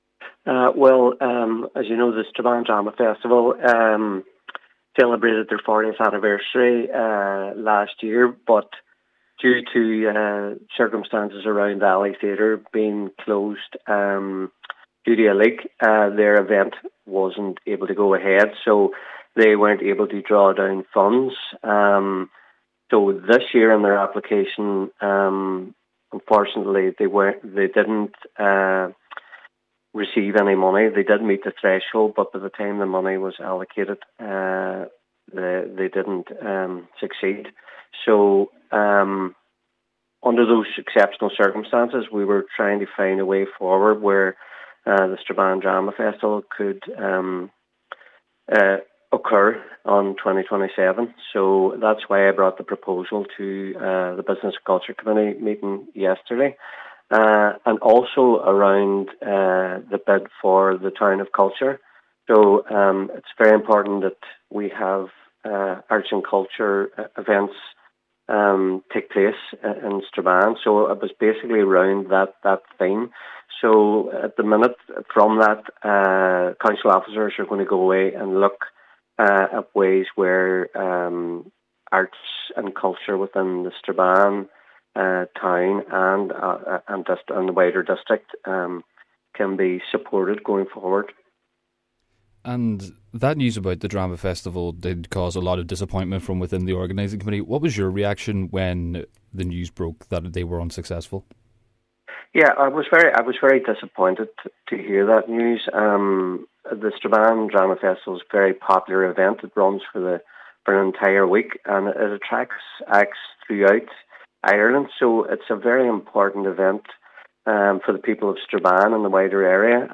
He says council officers are now working to see what funding can be found: